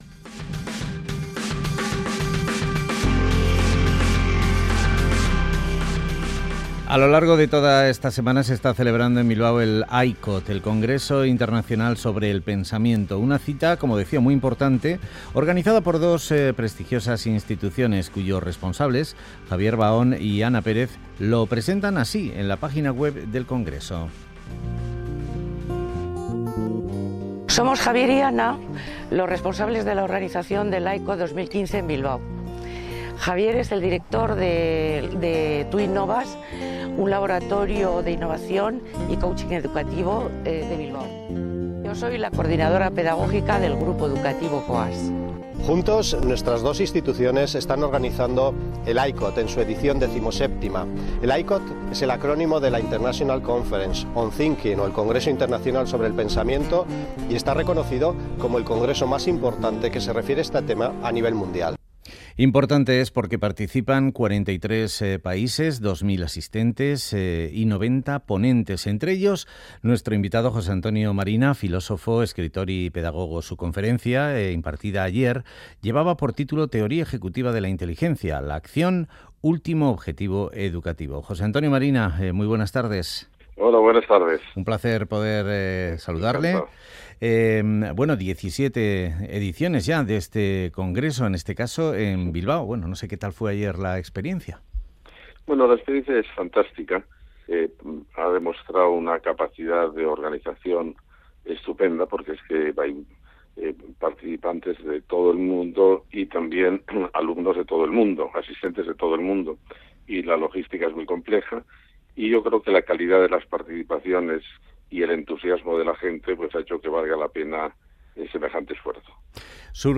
El filósofo, escritor y pedagogo Jose Antonio Marina reflexiona sobre la necesidad de seguir aprendiendo y de plantear un sistema educativo de autogestión.